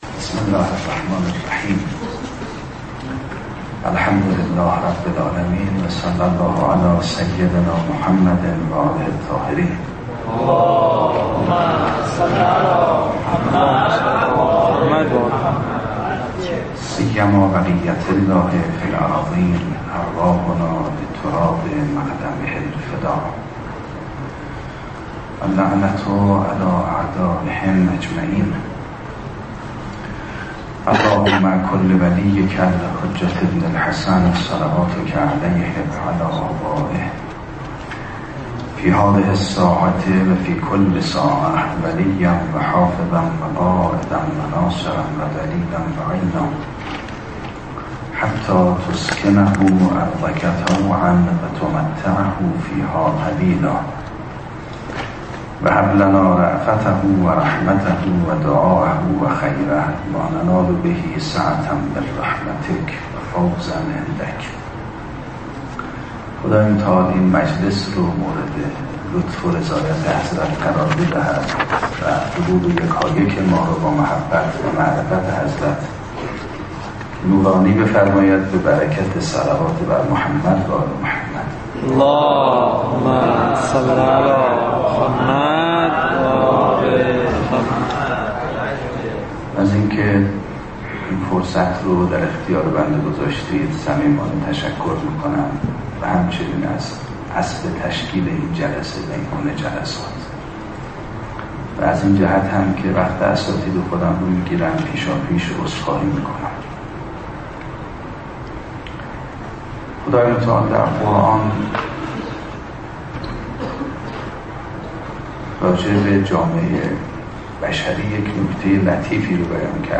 سخنرانی استاد میرباقری در همایش ملی اربعین :: پورتال جامع اربعین